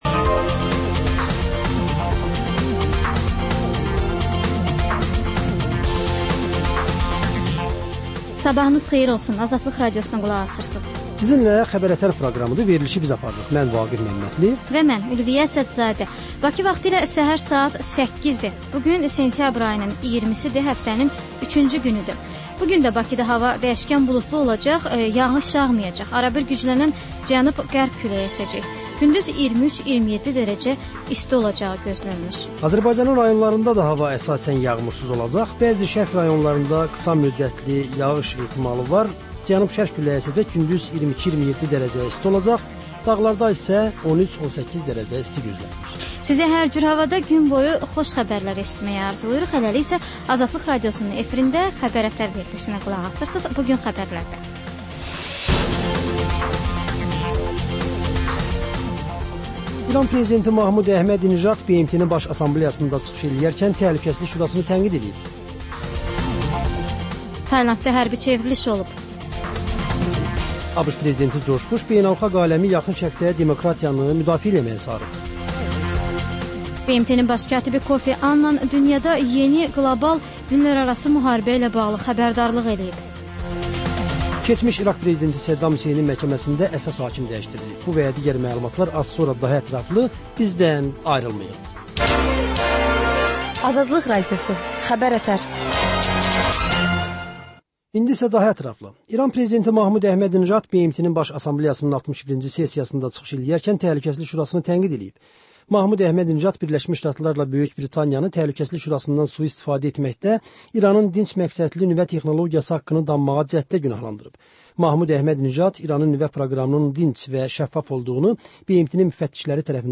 Bugün nələr gözlənir, nələr baş verib? Xəbər, reportaj, müsahibə. Sonra: Kontekst: Təhlil, müsahibə və xüsusi verilişlər.